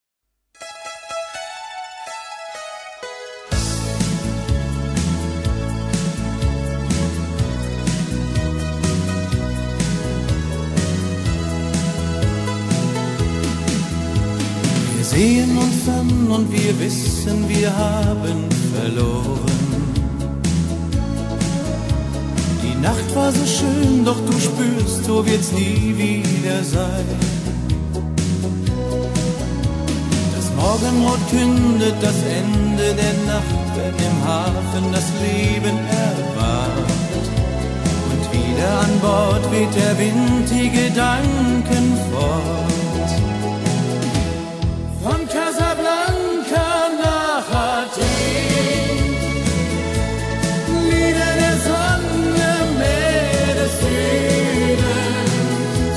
in het duits gezongen!